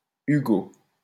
French: [yɡo]